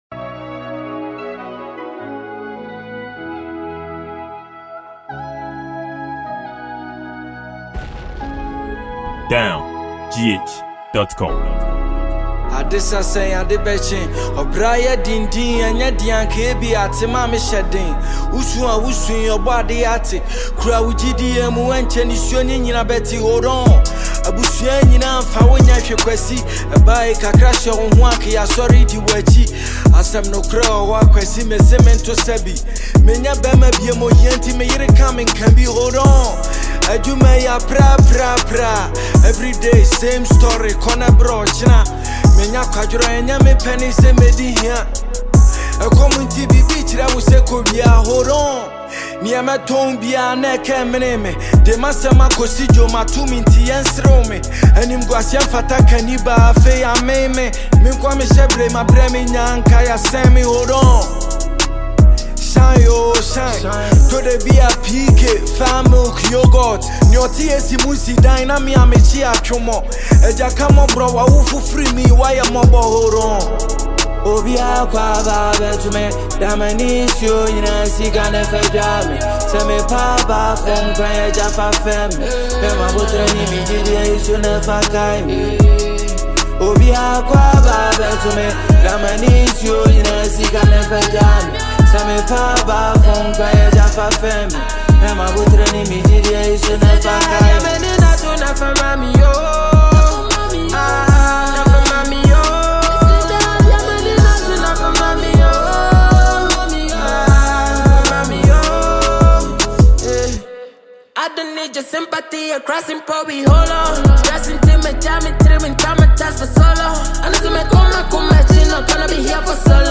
Genre: Hiphop